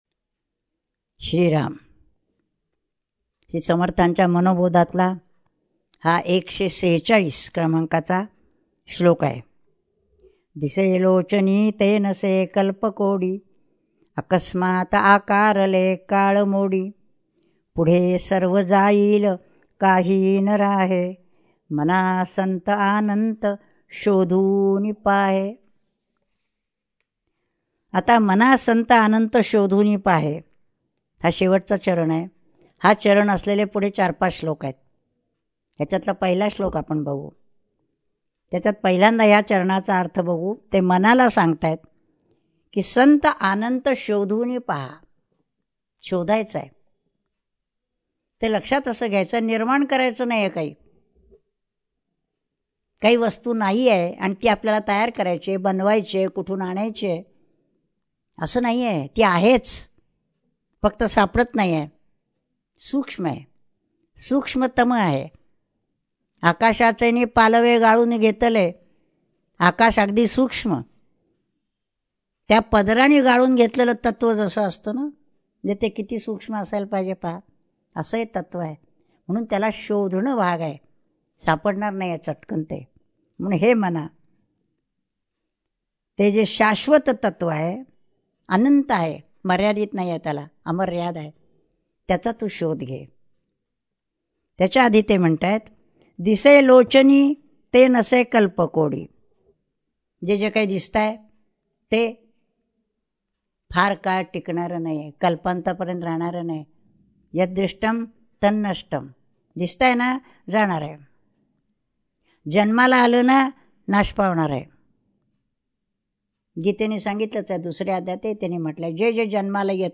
श्री मनाचे श्लोक प्रवचने श्लोक 146 # Shree Manache Shlok Pravachane Shlok 146